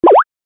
دانلود آهنگ موس 10 از افکت صوتی اشیاء
جلوه های صوتی
برچسب: دانلود آهنگ های افکت صوتی اشیاء دانلود آلبوم صدای کلیک موس از افکت صوتی اشیاء